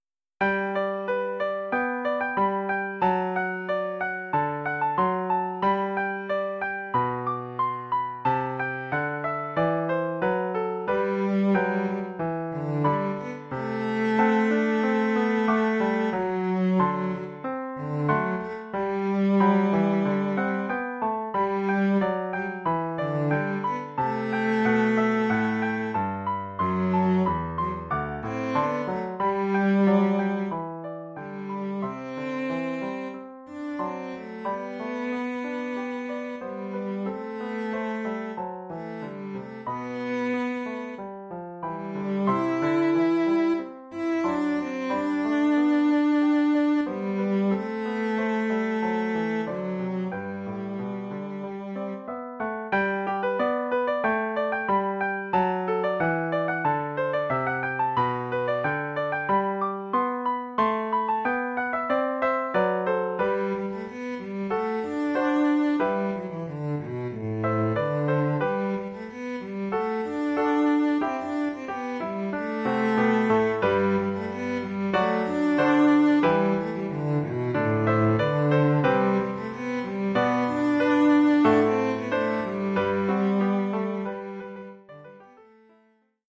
Oeuvre pour violoncelle et piano.